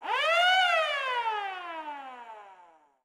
FCVolcanoAlarm.wav